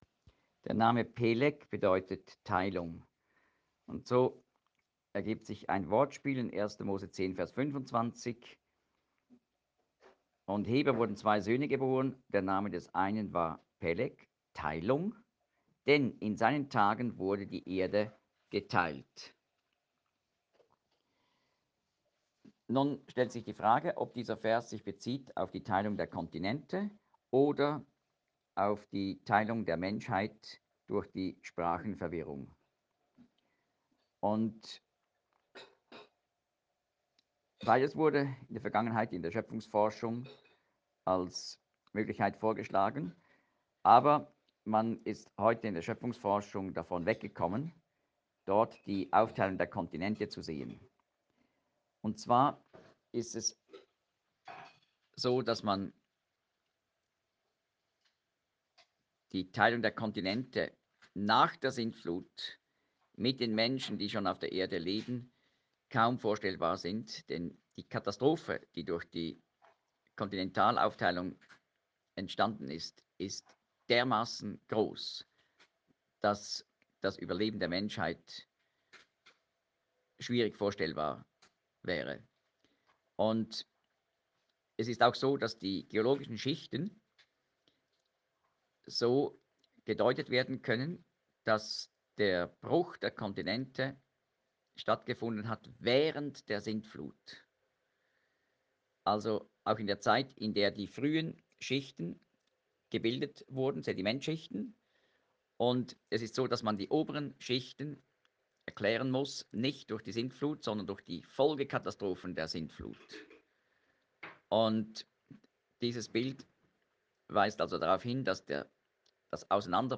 More Articles of the Category Lectures (282)